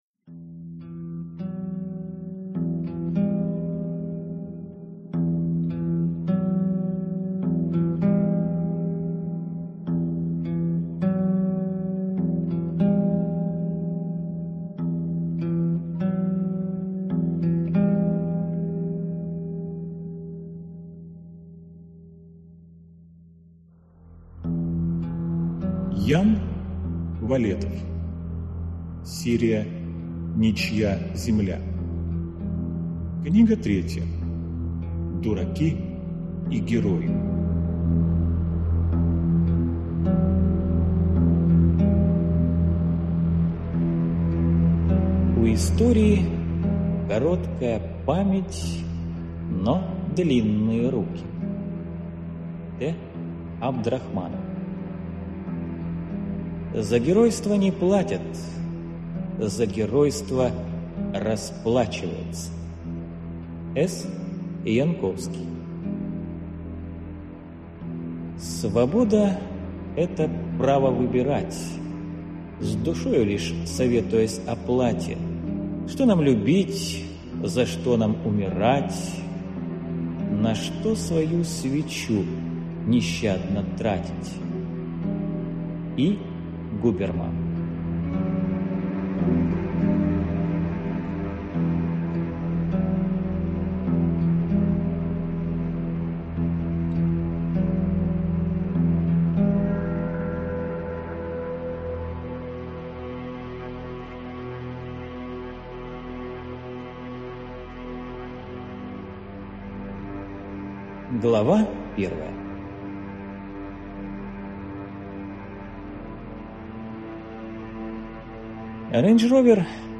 Аудиокнига Дураки и герои | Библиотека аудиокниг